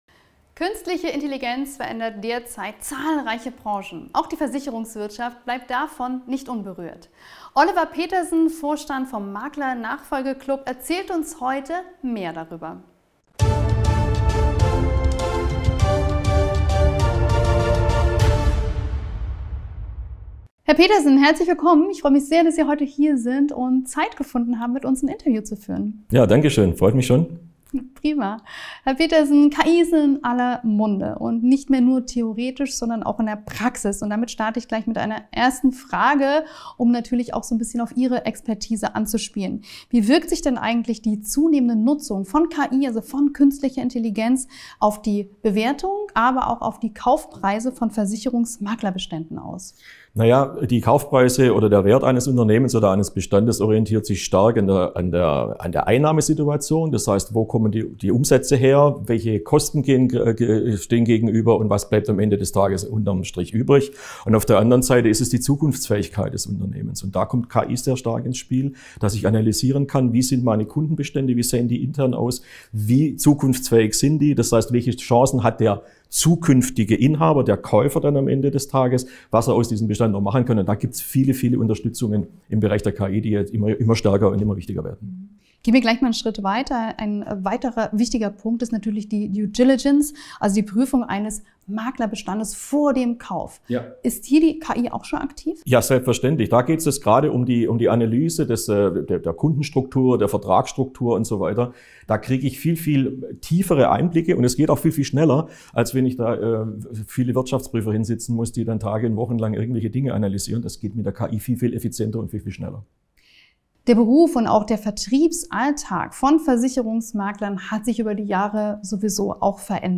Wie können Makler KI-Technologien wie KI-Telefonassistenten oder Chatbots sinnvoll einsetzen? In diesem Interview